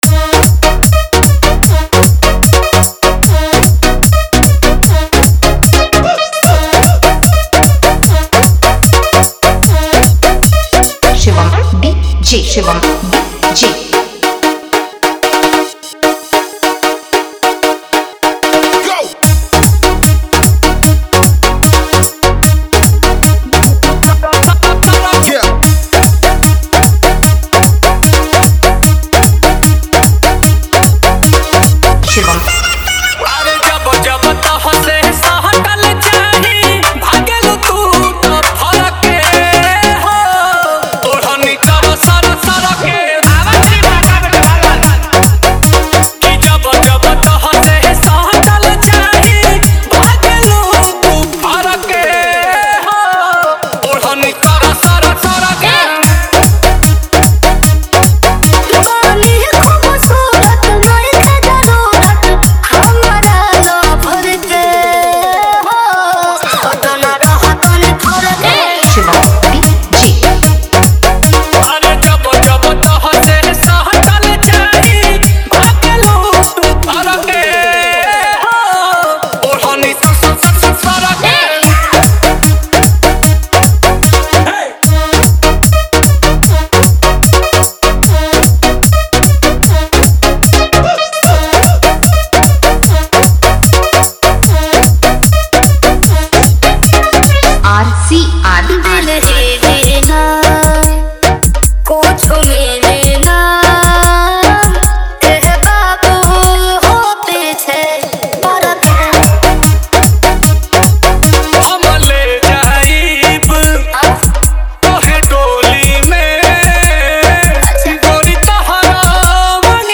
New Bhojpuri Song